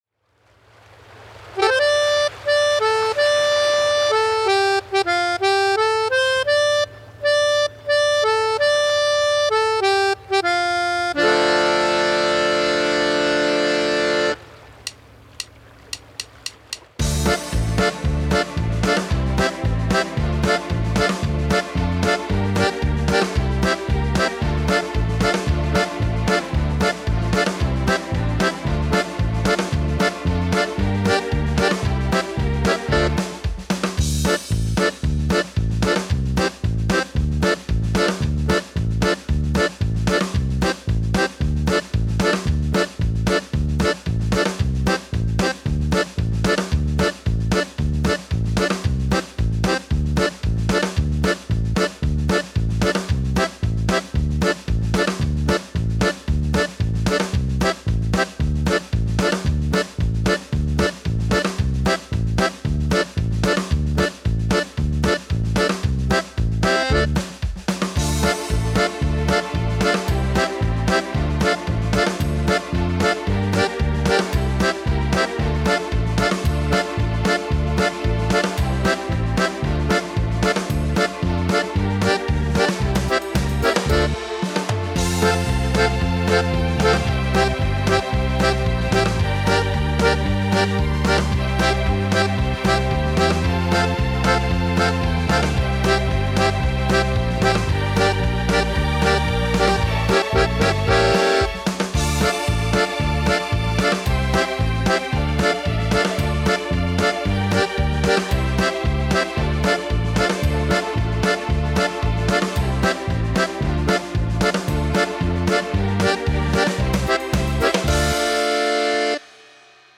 Übungsaufnahmen - Haul Away Joe
Runterladen (Mit rechter Maustaste anklicken, Menübefehl auswählen)   Haul Away Joe (Playback)